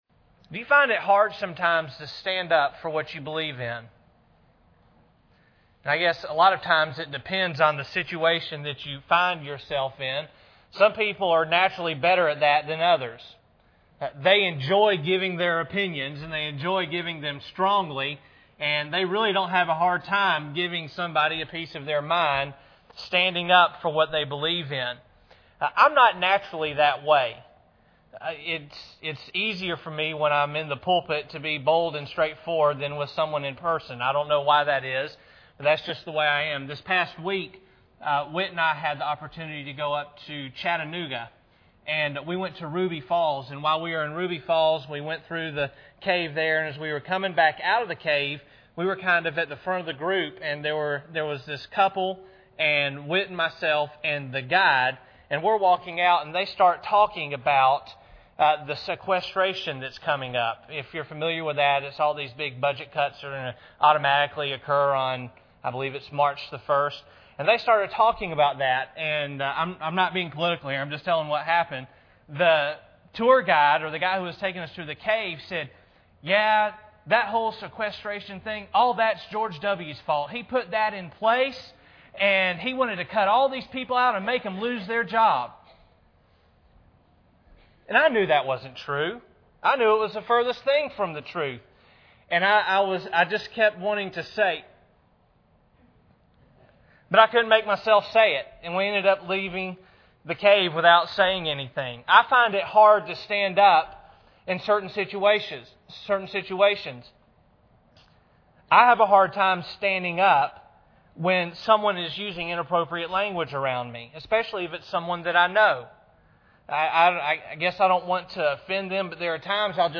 1 Peter 1:1 Service Type: Sunday Evening Bible Text